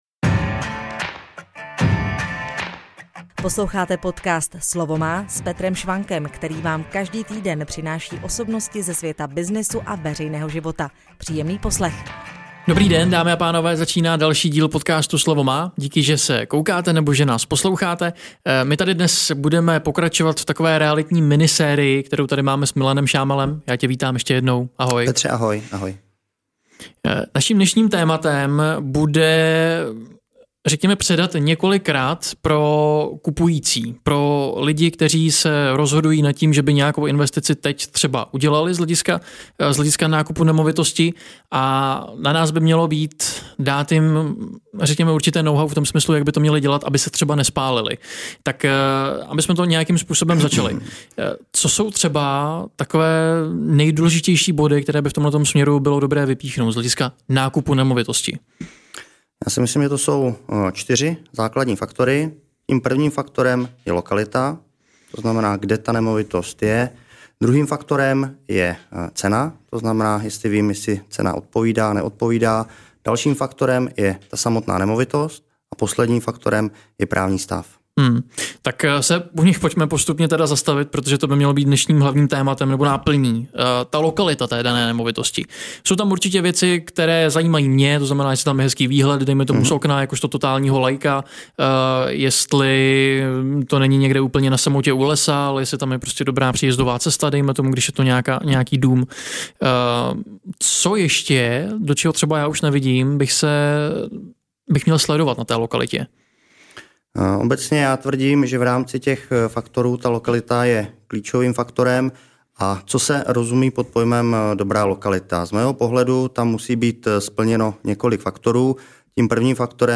V tomto rozhovoru nabízí pomoc především lidem, kteří se zajímají o nákup nemovitosti.